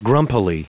Prononciation du mot grumpily en anglais (fichier audio)
Prononciation du mot : grumpily
grumpily.wav